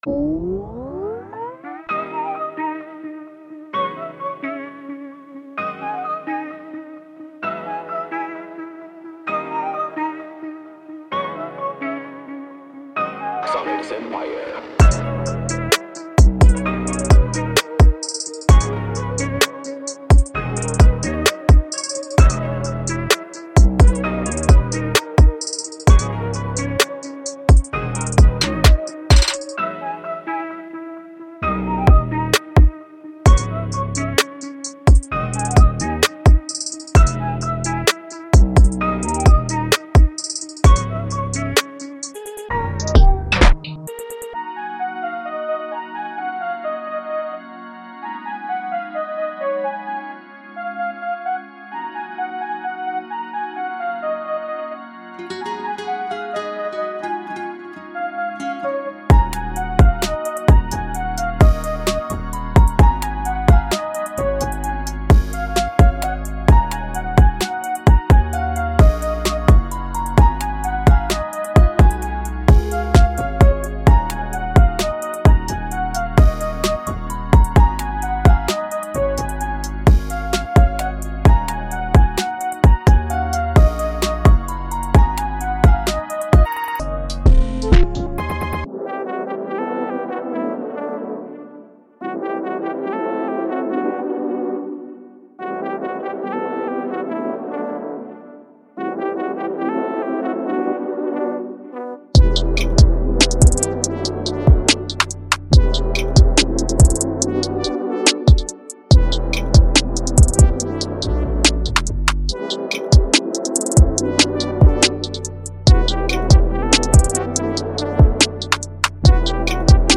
Hip HopTrap